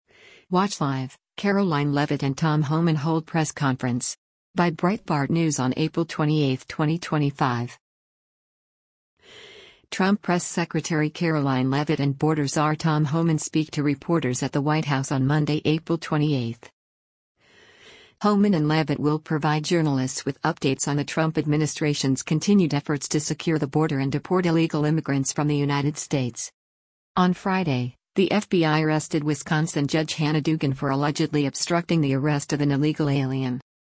Trump Press Secretary Karoline Leavitt and border czar Tom Homan speak to reporters at the White House on Monday, April 28.